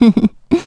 Gremory-Vox-Laugh.wav